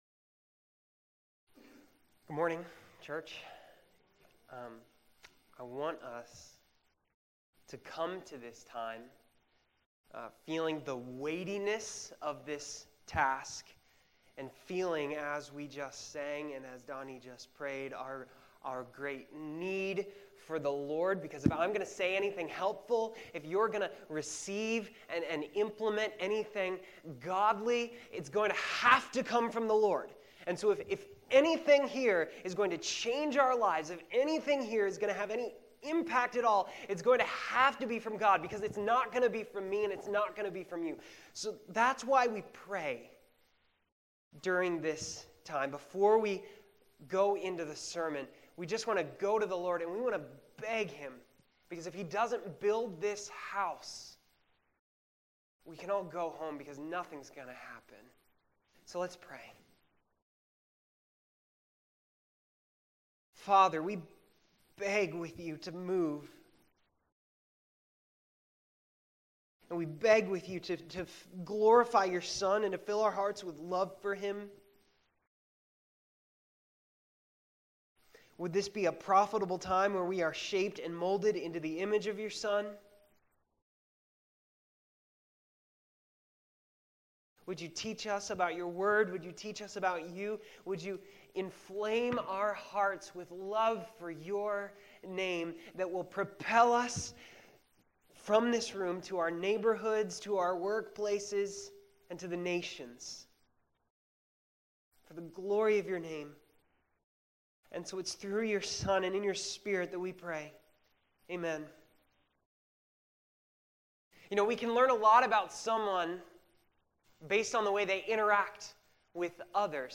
July 17, 2016 Morning Worship | Vine Street Baptist Church